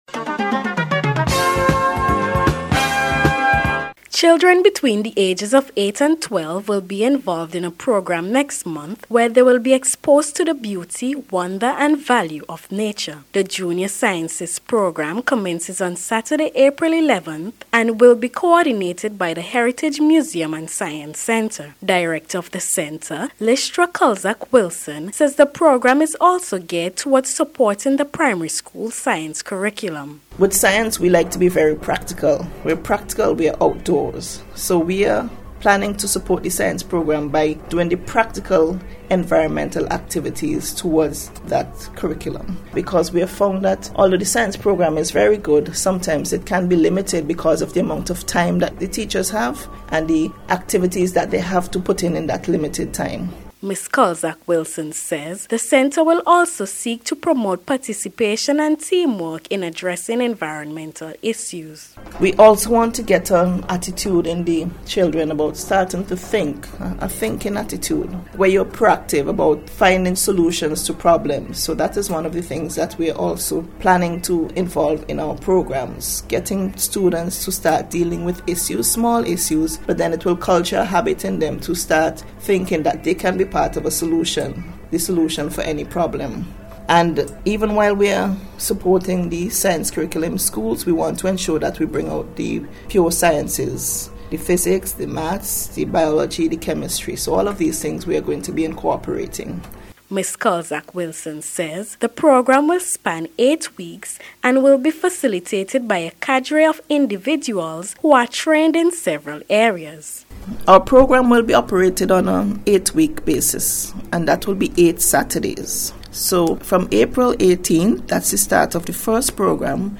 JUNIOR-SCIENCEISTS-PROGRAM-REPORT-.mp3